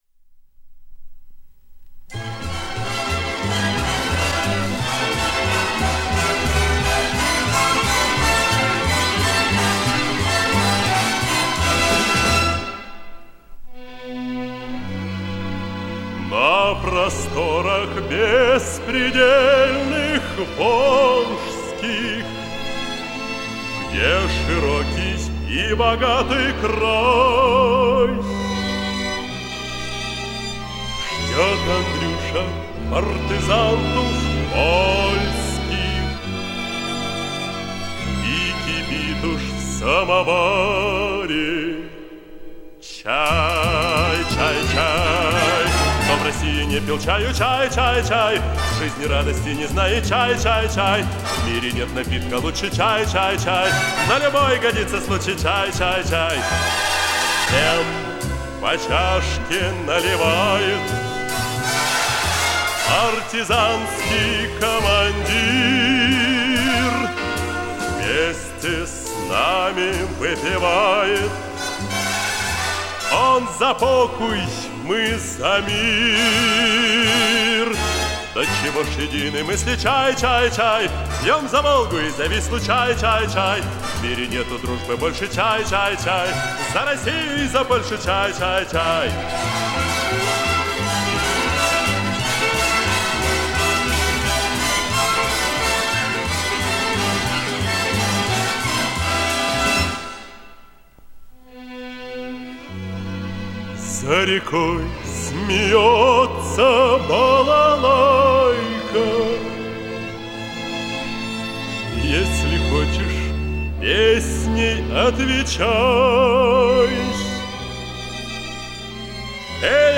Жанр: Pop (Советская эстрада)